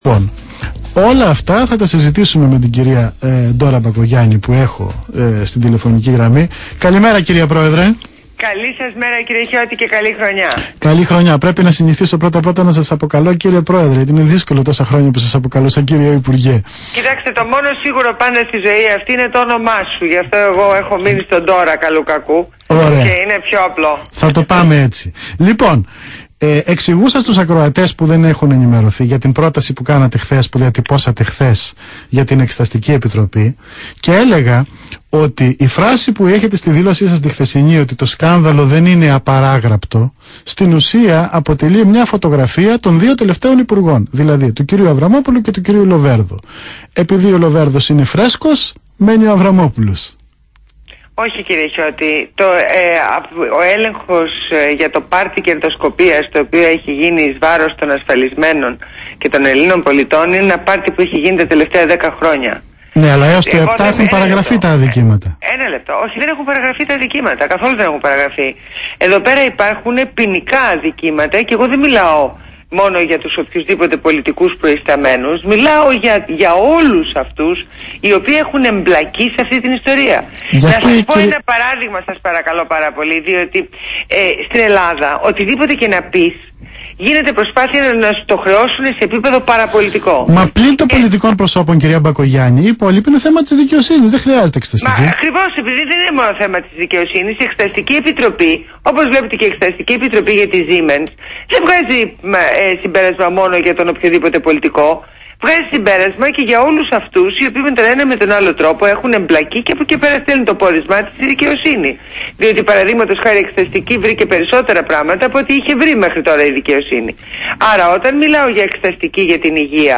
Συνέντευξη Ντόρας Μπακογιάννη στο ραδιόφωνο ΒΗΜΑ fm 99.5 | Πρόεδρος
Ακούστε τη συνέντευξη που έδωσε η πρόεδρος του κινήματος στο ραδιόφωνο BHMA fm 99.5.